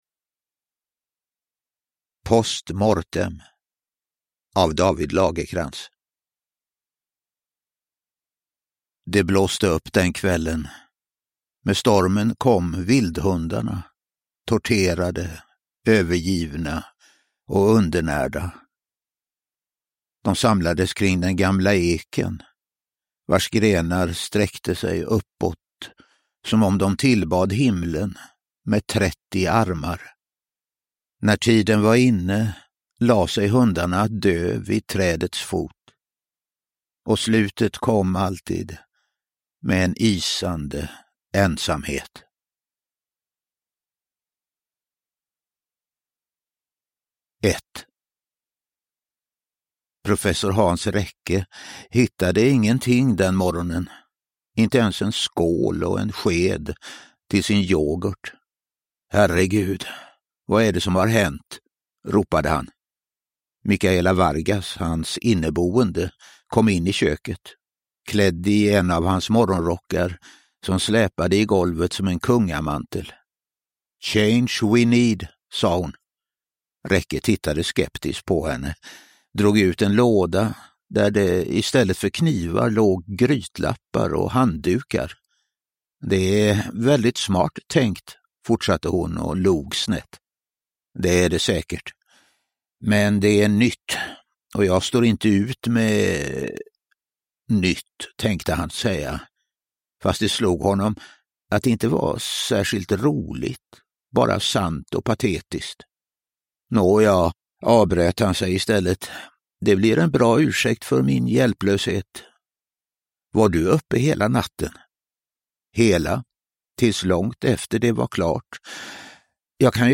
Post Mortem – Ljudbok
Uppläsare: Reine Brynolfsson